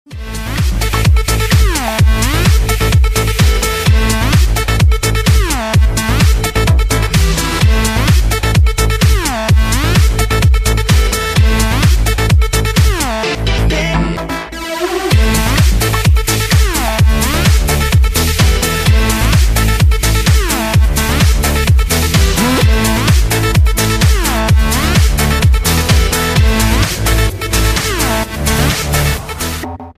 • Качество: 128, Stereo
громкие
Electronic
EDM
club
Стиль: electro house